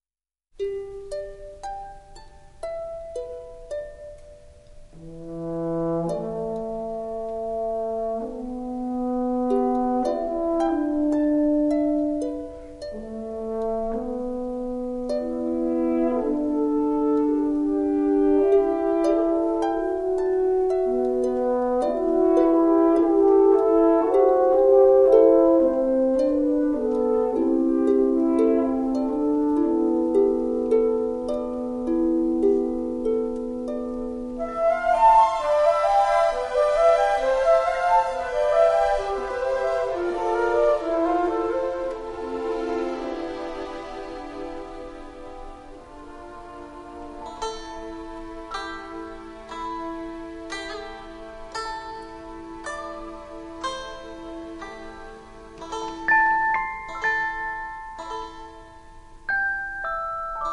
CD 1 Original Motion Picture Soundtrack